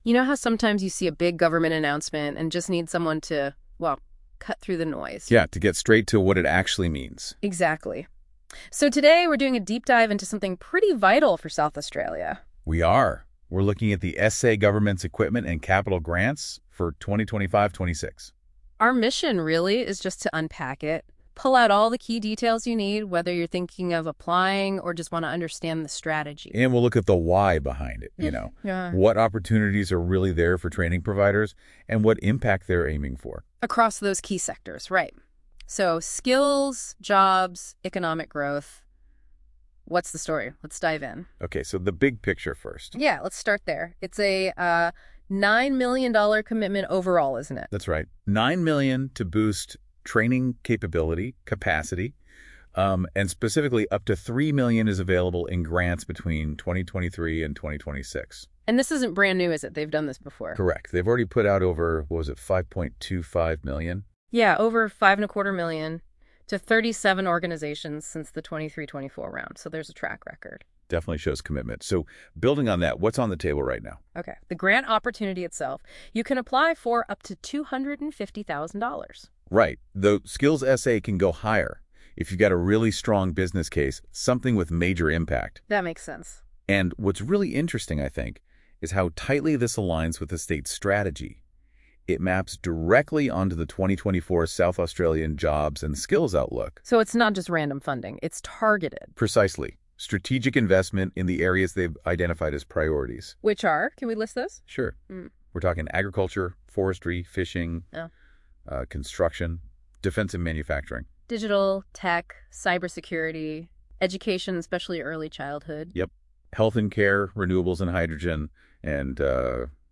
Skills SA is trialling an audio overview of the 2025-26 Equipment and Capital Grants Guidelines if you prefer a verbal presentation.